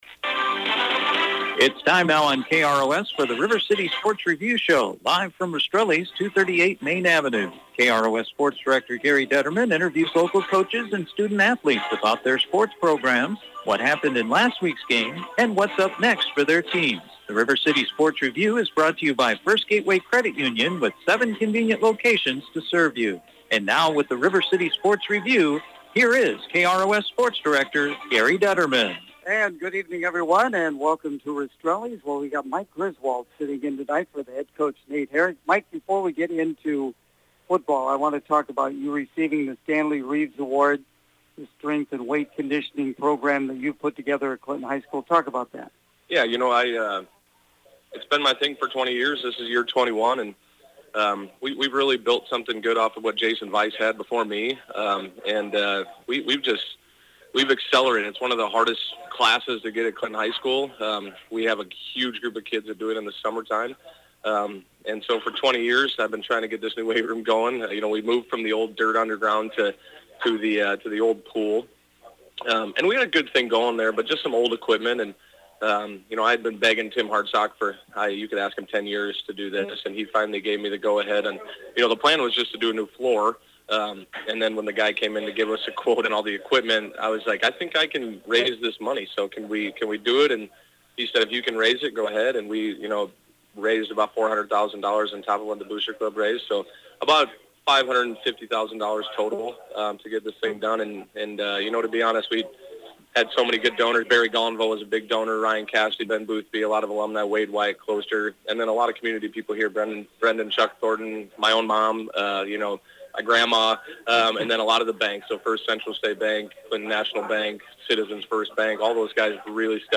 The River City Sports Review Show on Wednesday night from Rastrelli’s Restaurant
with the area coaches to preview this weekends sports action.